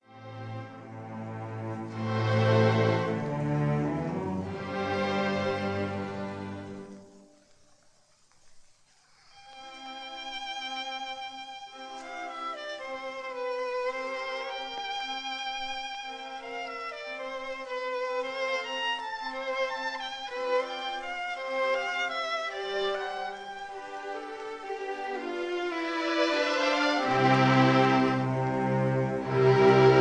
conductor
historic 1936 recording